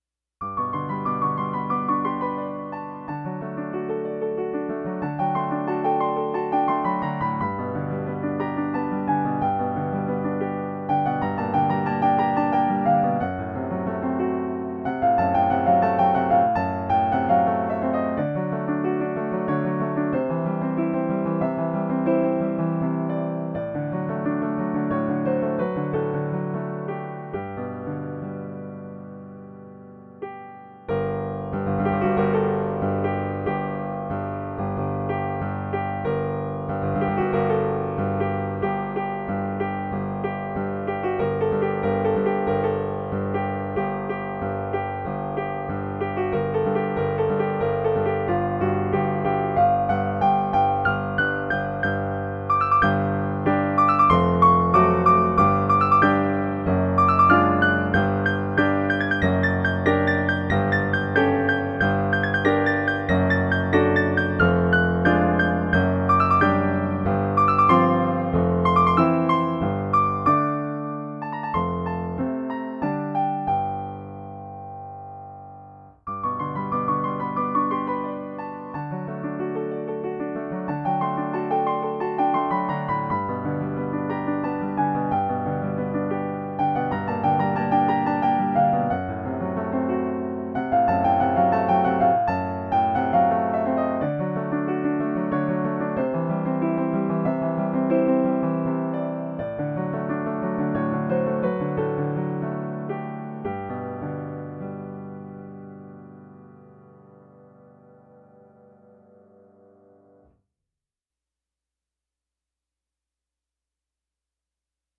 For Solo Piano, Level 5 - Advanced
Delicate like a butterfly.